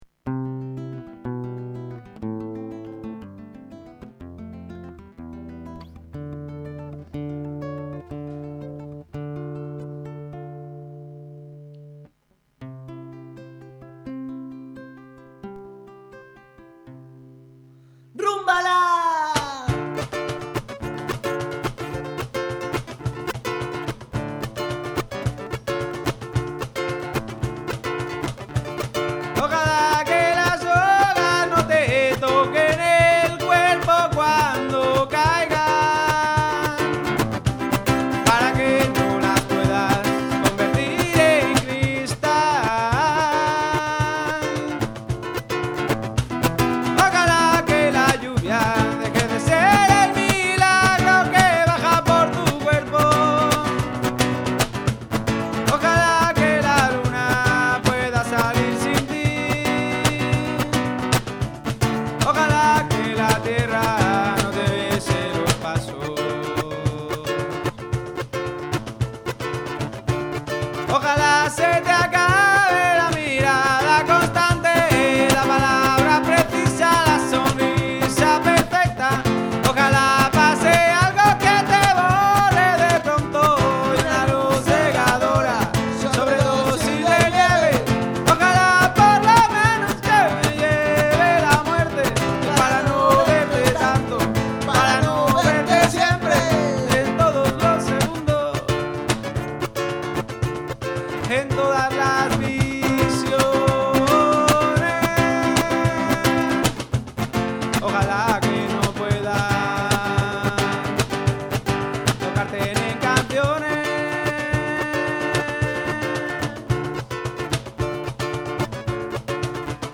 voz y guitarra
2ª voz y percusiones
alboka, kena y wisell